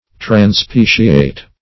Search Result for " transpeciate" : The Collaborative International Dictionary of English v.0.48: Transpeciate \Tran*spe"ci*ate\, v. t. [Pref. trans- + L. species form.]